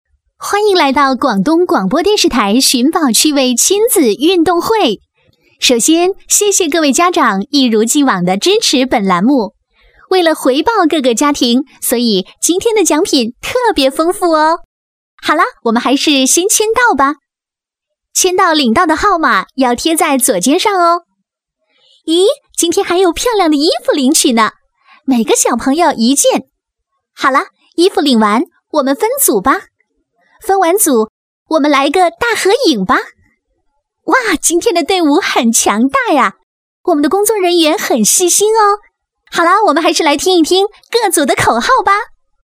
职业配音员全职配音员成熟
• 女S114 国语 女声 宣传片 广东电视台《亲子运动会》-电视配音-欢快 亲切甜美